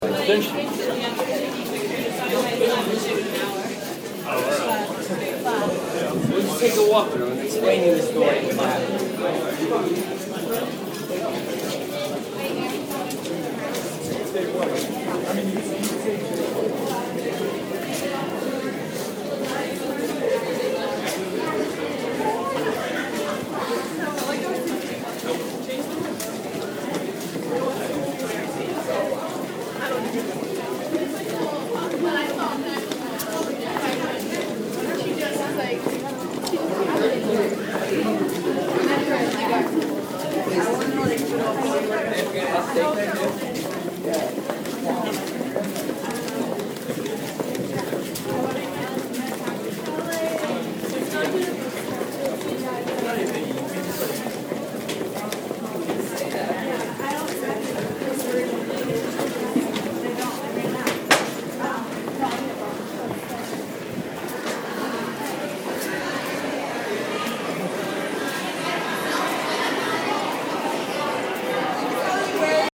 Field Recording #3
The unispan people talking, footsteps, the rustling of coats and bags